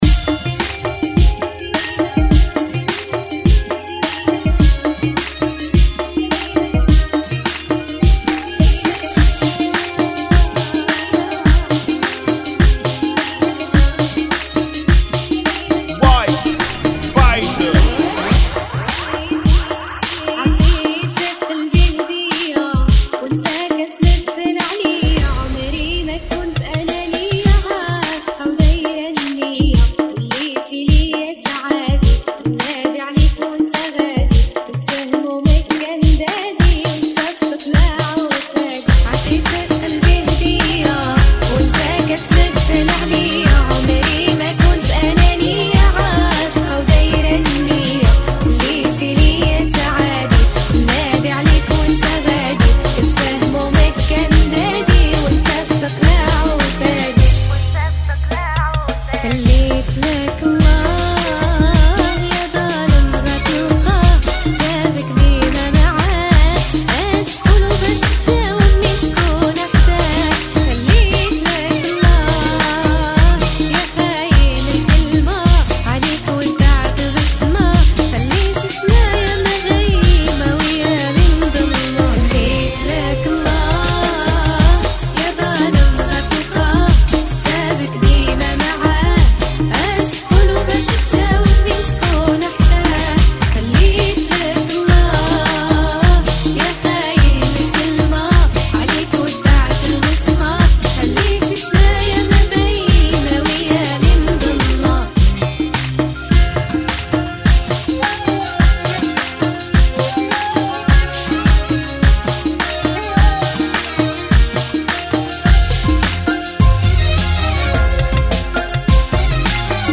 Funky [ 105 Bpm ]